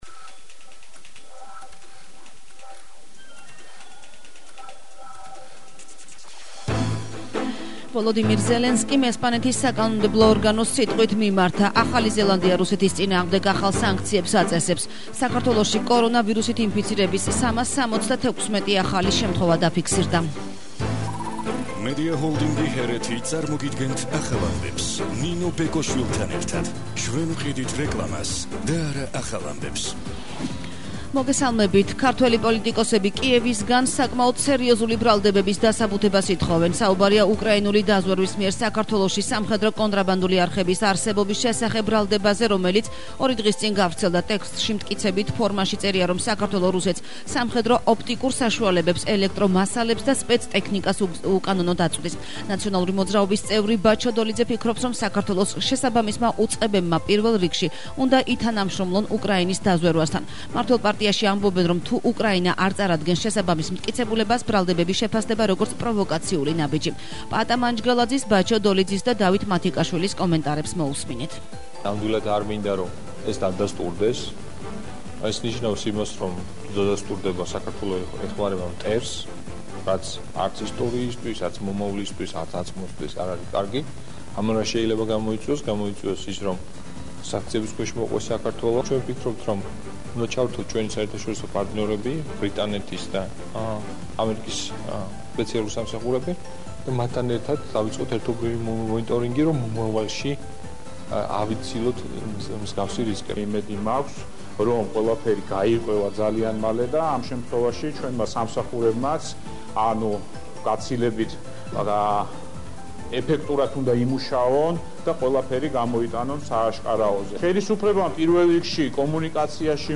ახალი ამბები 11:00 საათზე – 06/04/22 - HeretiFM